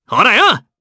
Gladi-Vox_Attack2_jp.wav